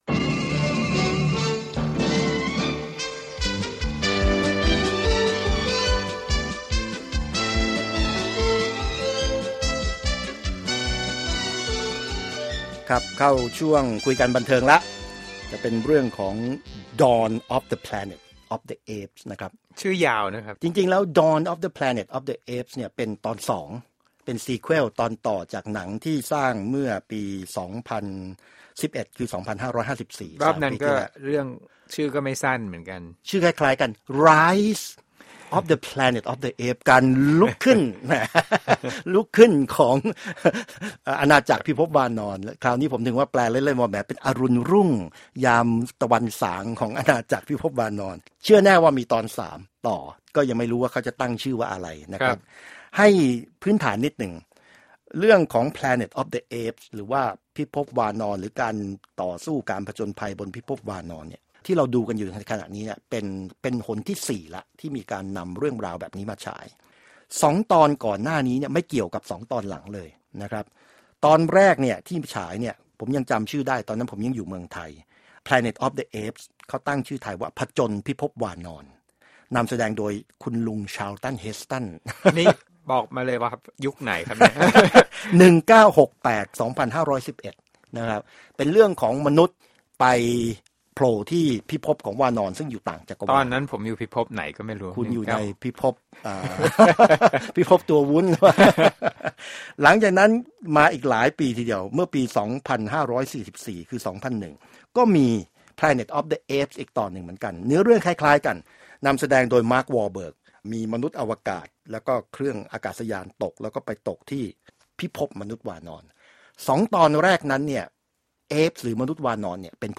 Movie Review: Dawn Planet of Apes